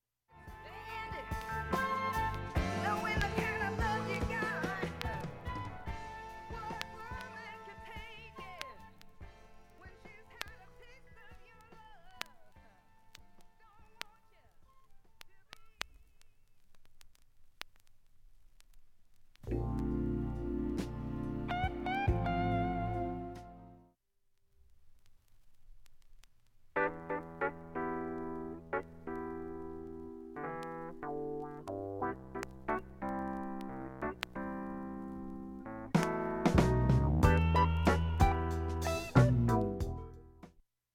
音質良好全曲試聴済み。
◆ＵＳＡ盤オリジナル
すべての曲が素晴らしいラヴ・ソング・アルバム。